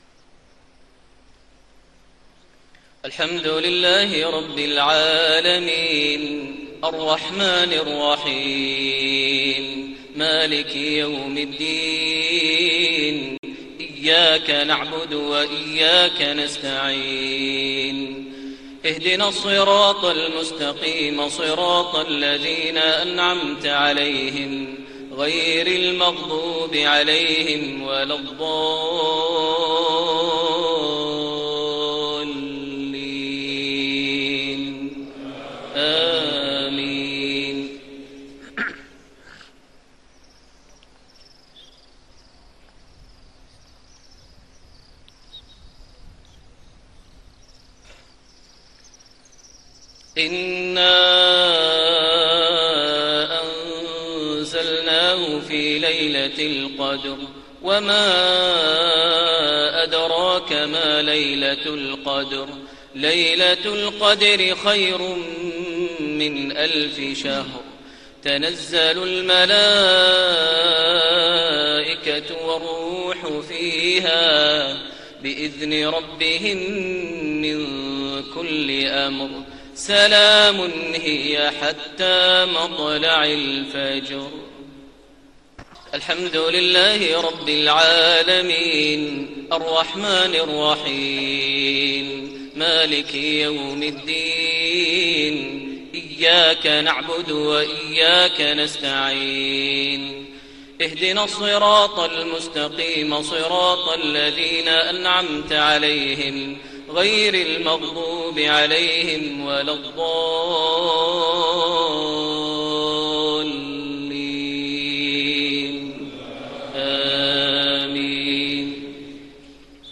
صلاة المغرب2-4-1432 سورتي القدر و الإخلاص > 1432 هـ > الفروض - تلاوات ماهر المعيقلي